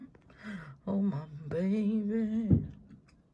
oh my baby Meme Sound Effect
Category: Reactions Soundboard